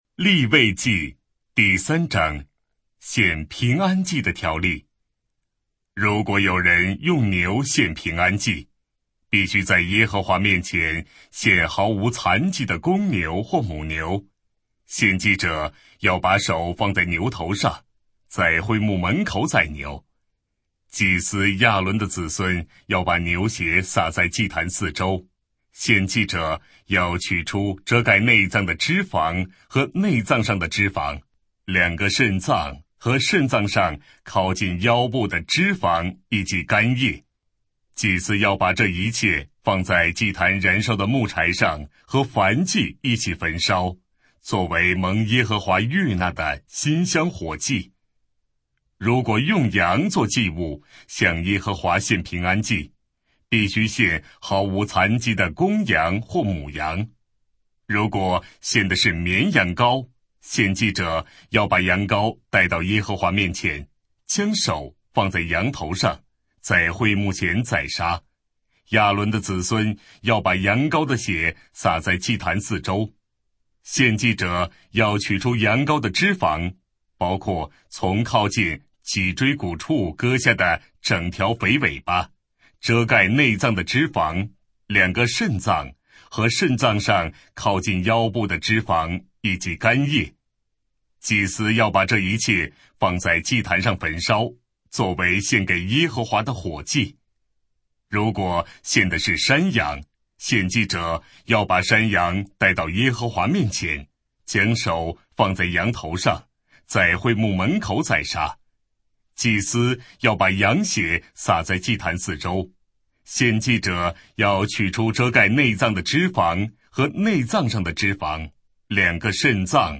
标签： 圣经朗读
当代译本朗读：利未记